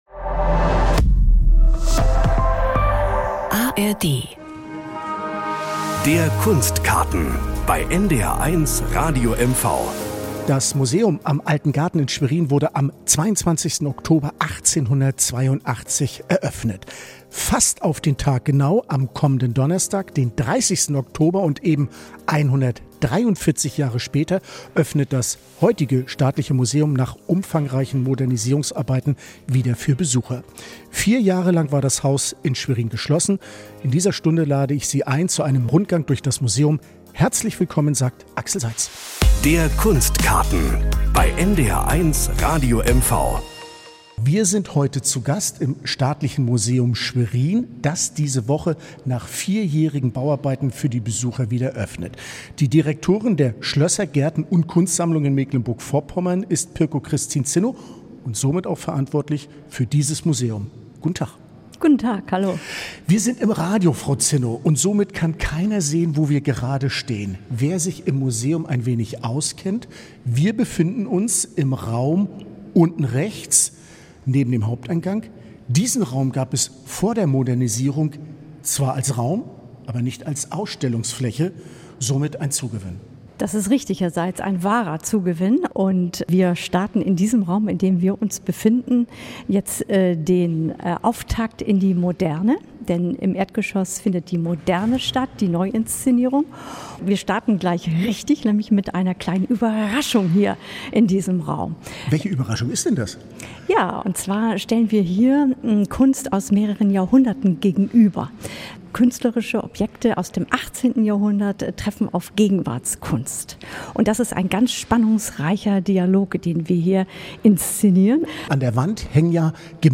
Das Staatliche Museum Schwerin war vier Jahre lang geschlossen - für Modernisierungsarbeiten. Mit mehr Ausstellungsffläche, bekannten Kunstwerken und großartigen Leihgabe präsentiert sich das Haus neu. Ein Museumsrundgang